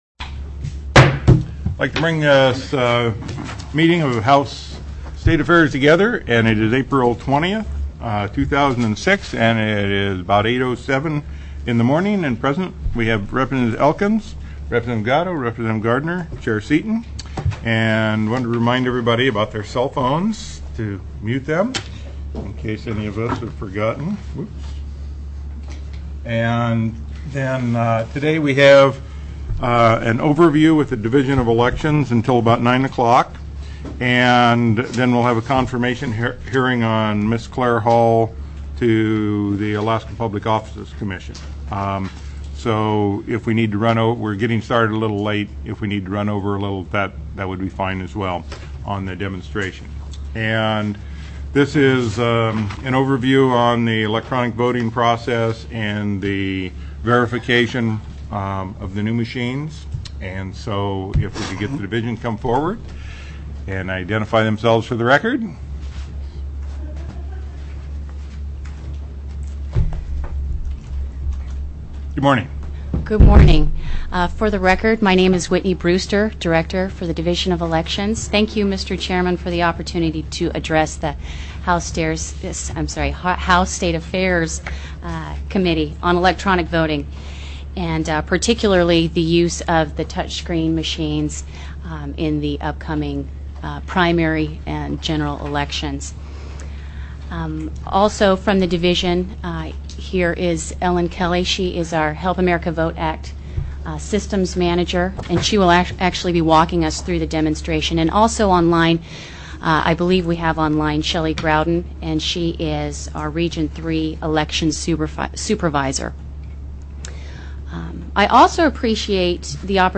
04/20/2006 08:00 AM House STATE AFFAIRS